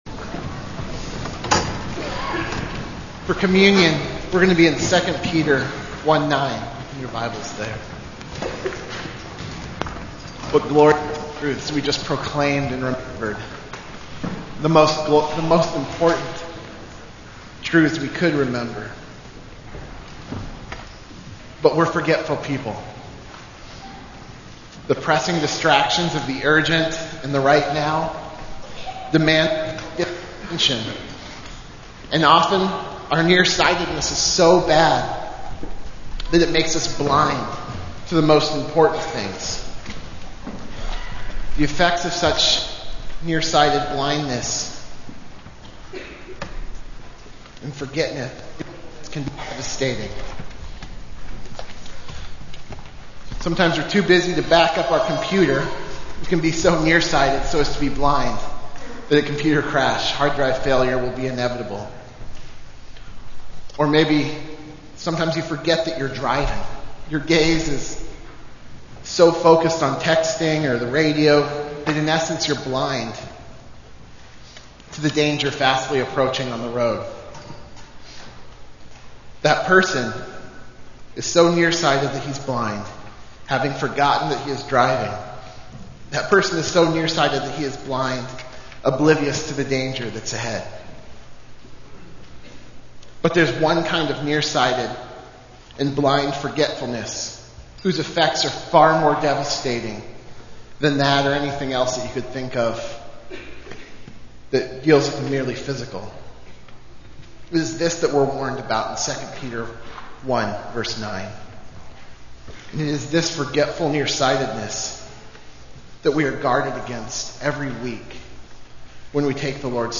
Service: Communion